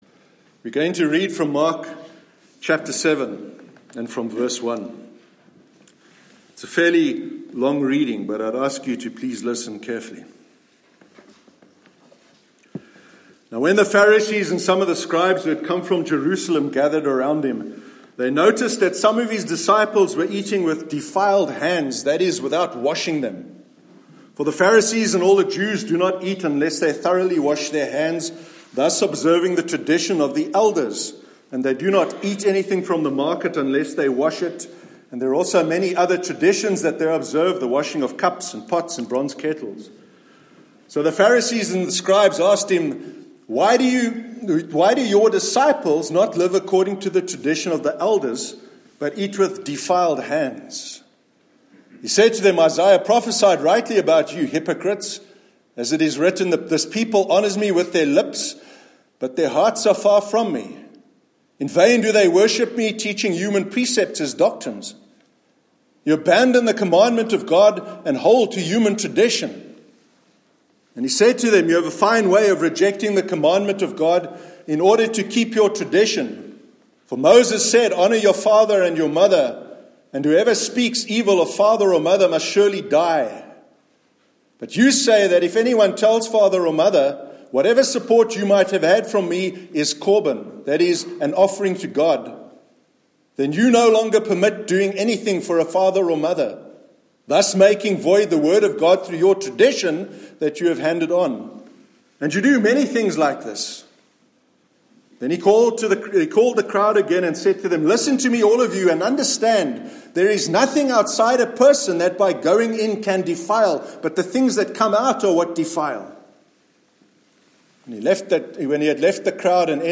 Faith and Understanding- Sermon 24th March 2019